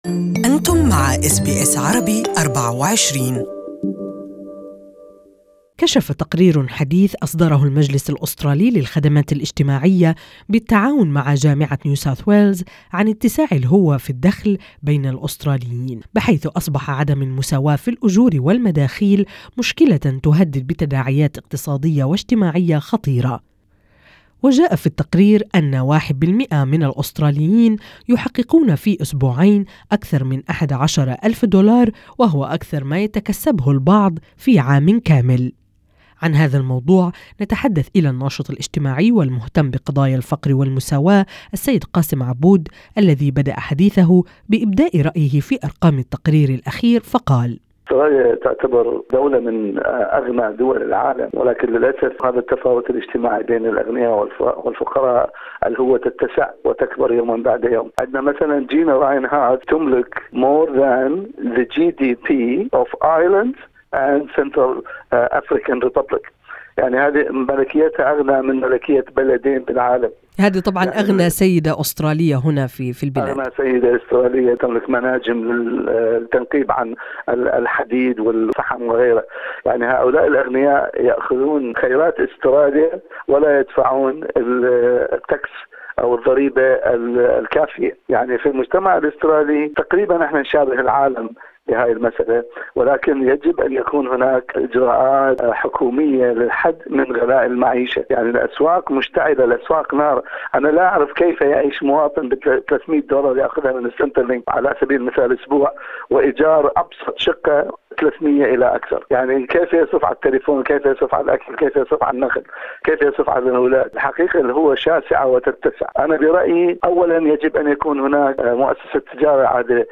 We speak to social activist